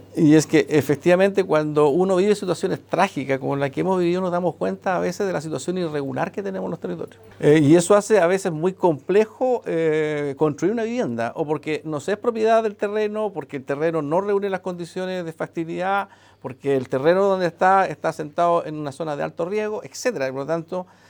El delegado Presidencial (s), Humberto Toro, agregó que en algunos casos ha sido difícil construir de forma efectiva, debido a problemas con los títulos de dominio, así como también el acceso de las empresas a zonas complejas.